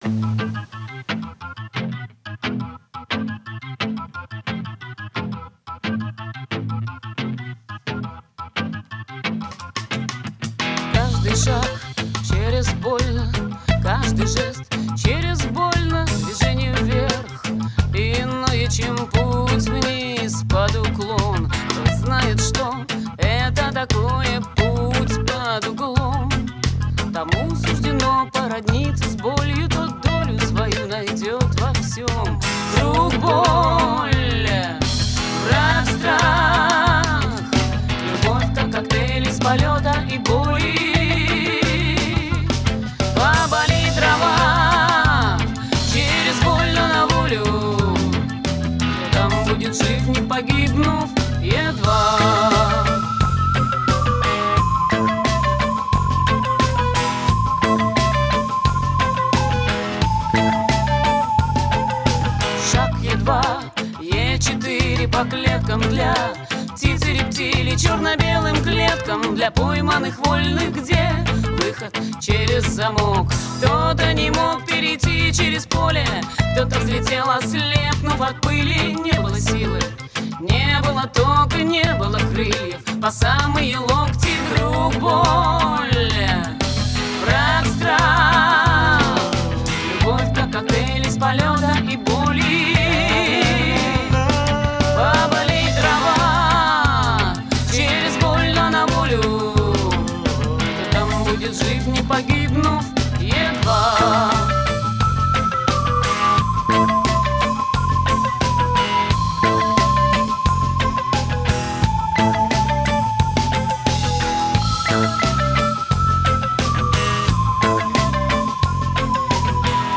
Студийный электрический альбом.
бас-гитара
клавиши
гитара
ударные, перкуссия